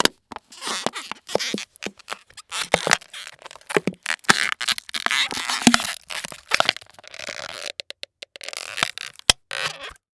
Plastic Bottle Crushing Sound
household
Plastic Bottle Crushing